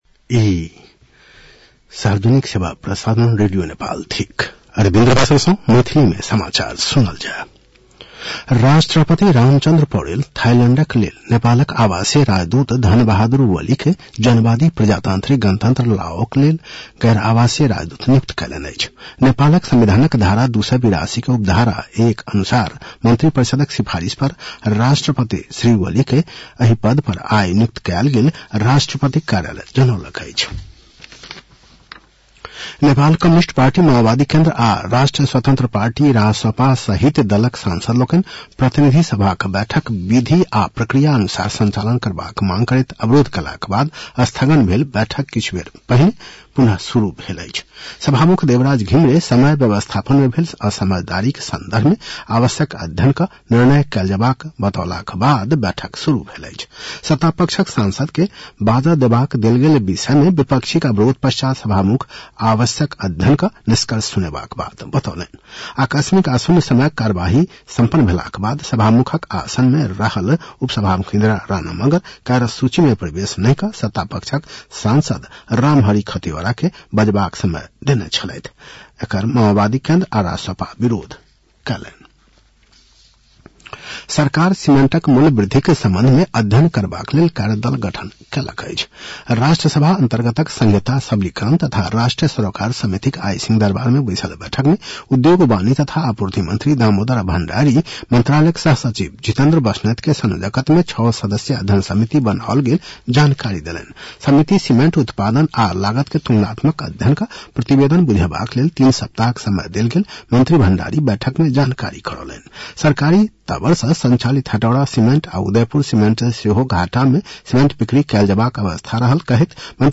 मैथिली भाषामा समाचार : ३० माघ , २०८१